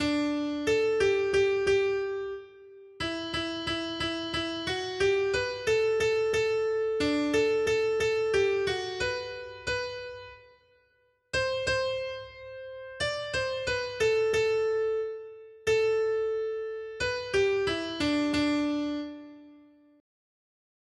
Noty Štítky, zpěvníky ol276.pdf responsoriální žalm Panna Maria Žaltář (Olejník) 276 Ž 132, 6-7 Ž 132, 9-10 Ž 132, 13-14 Skrýt akordy R: Vstaň, Hospodine, vejdi na místo svého odpočinku, ty i tvá vznešená archa! 1.